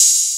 Open Hats
[ACD] - Lex Hat.wav